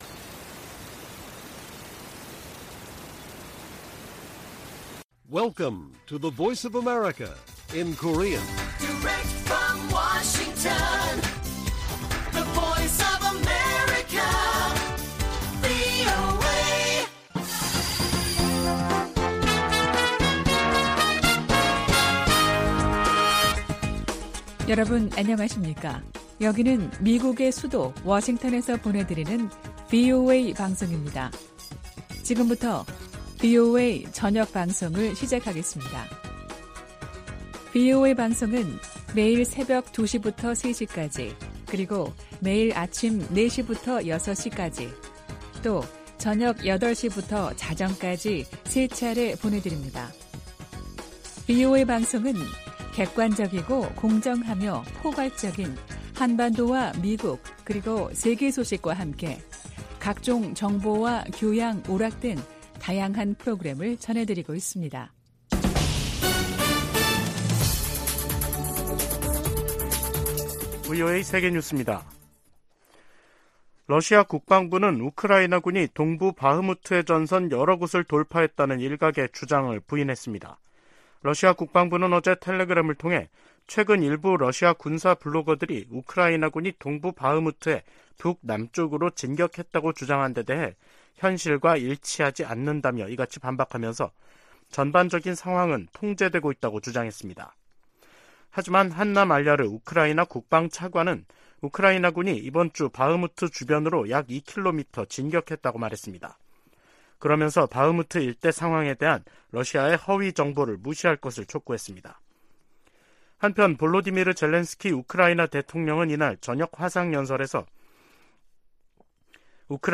VOA 한국어 간판 뉴스 프로그램 '뉴스 투데이', 2023년 5월 12일 1부 방송입니다.